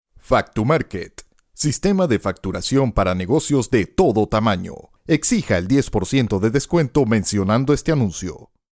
spanisch Südamerika
Sprechprobe: Werbung (Muttersprache):